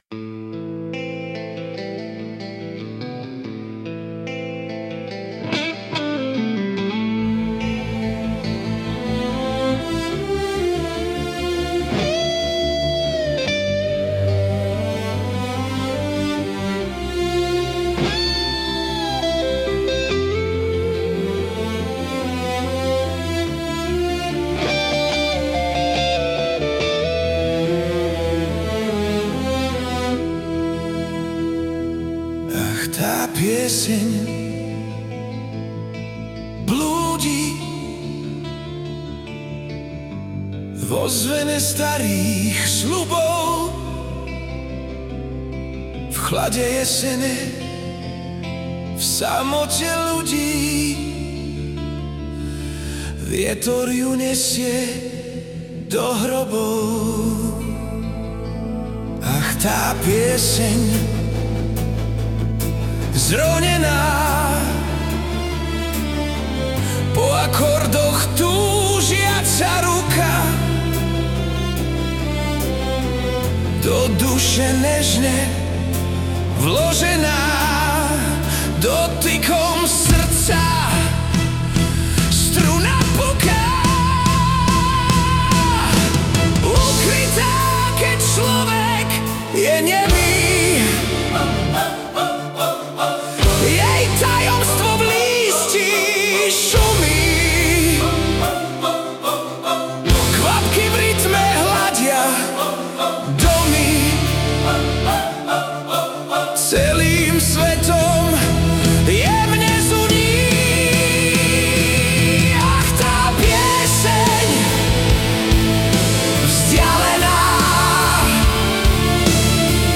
Hudba a spev AI
Balady, romance » Ostatní